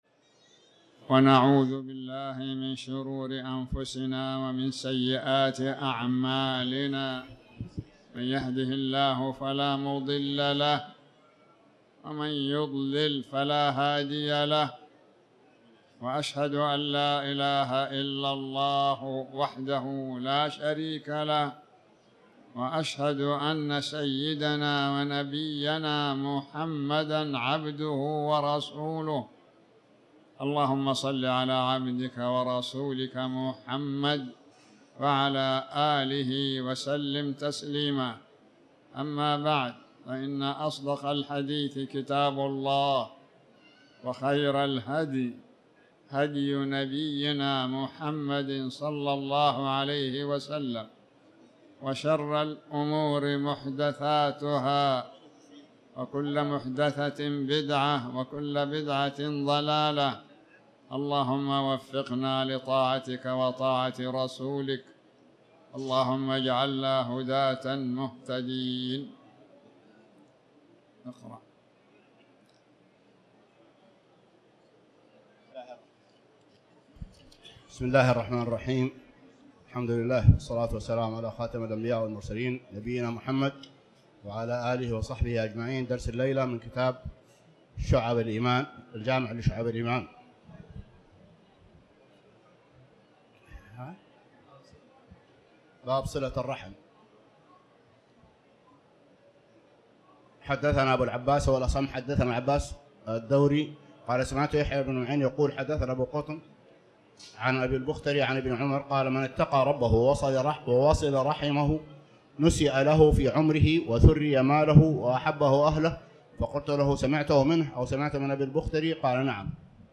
تاريخ النشر ٧ جمادى الآخرة ١٤٤٠ هـ المكان: المسجد الحرام الشيخ